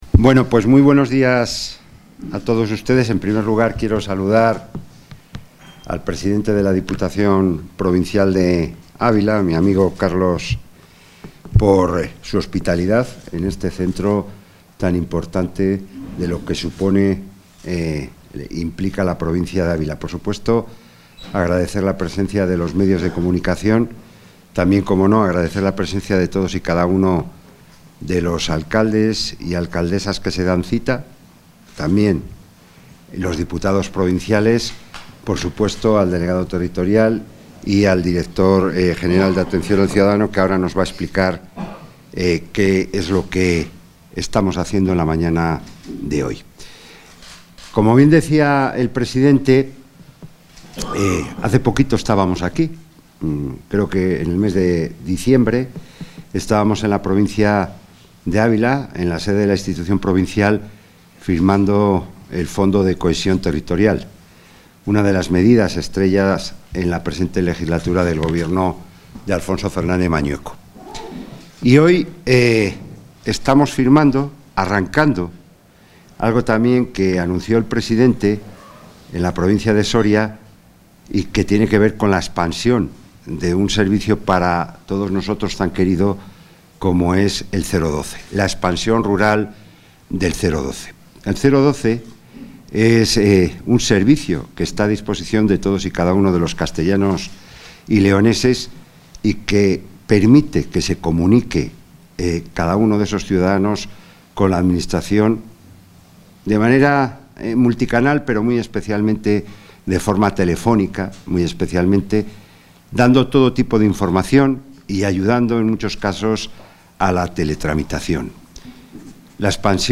Intervención del consejero de la Presidencia.